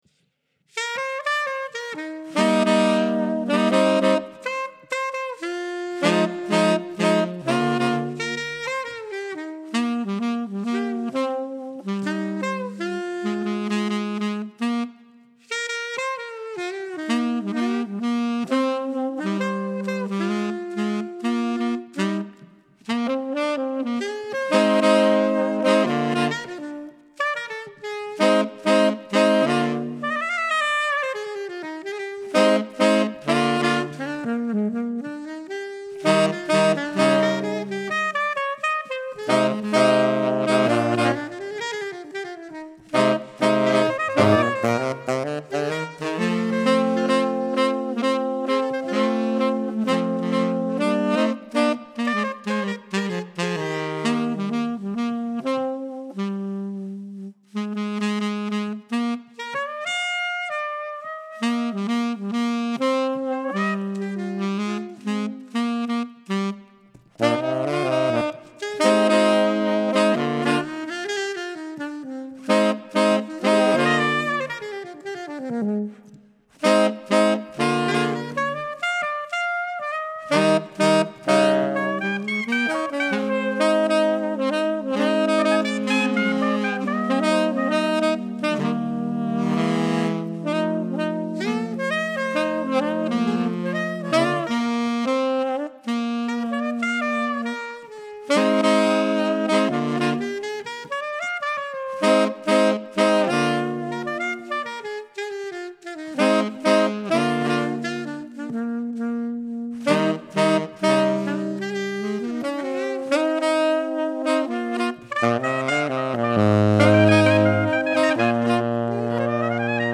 Bespoke Ceremony Recording
arranged in 2025 for a funeral ceremony.